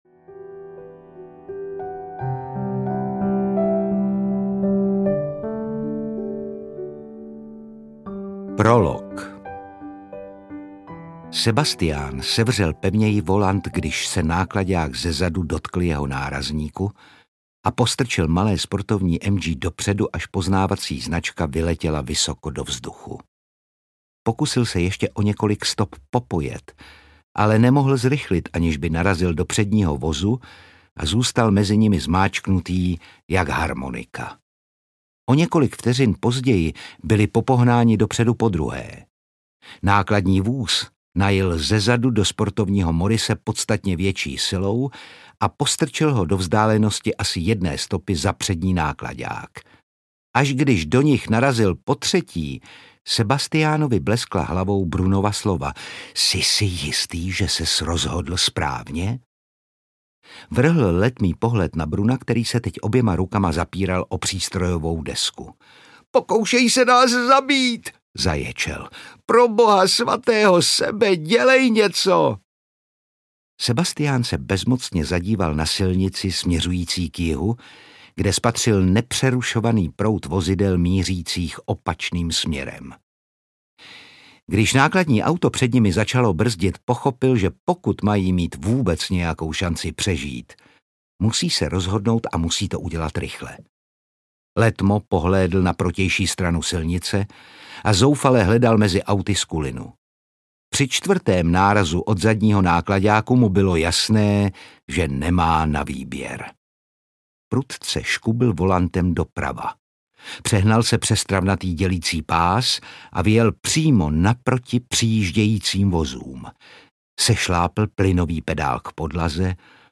Příliš odvážné přání audiokniha
Ukázka z knihy
• InterpretOtakar Brousek ml.